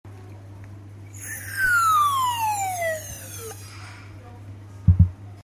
Subscribe Next Anyone know what the main bird is in this short clip?